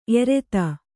♪ ereta